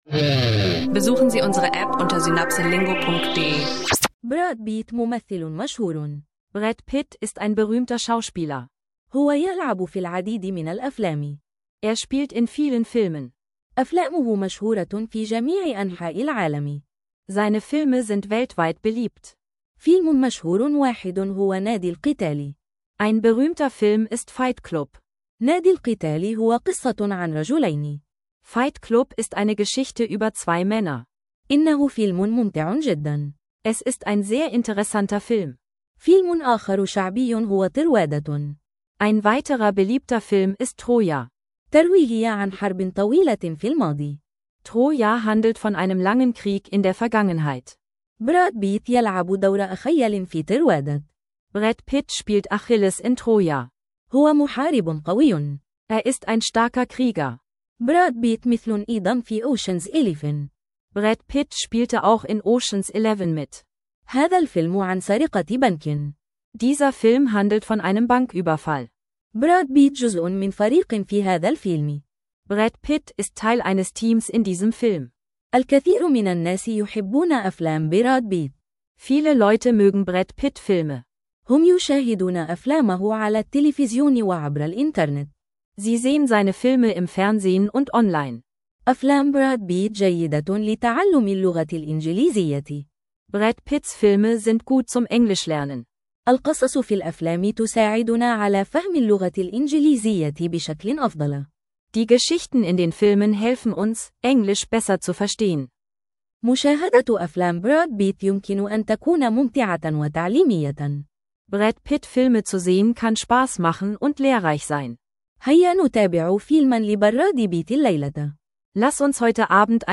Diese Episode von SynapseLingo bietet dir einen interaktiven Arabisch Audio Sprachkurs mit vielfältigen Themen wie Filme, Sport, Leben und Medien.